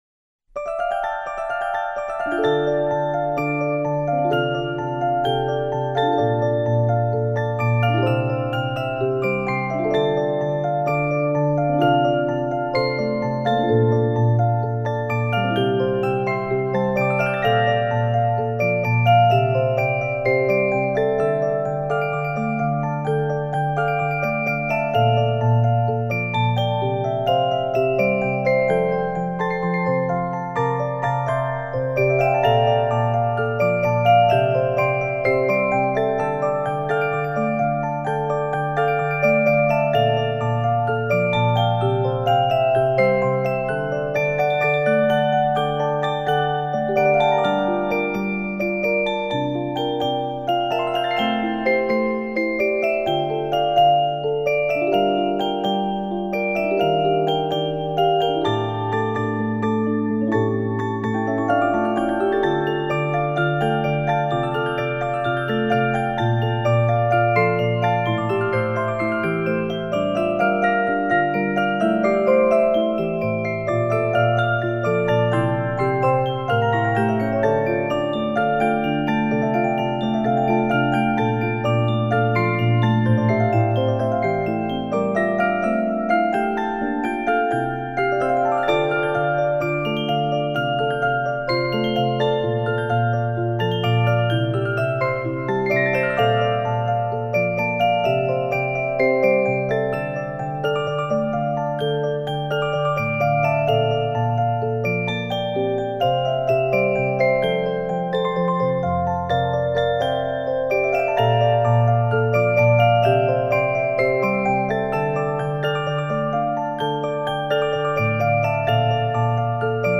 (no vocals)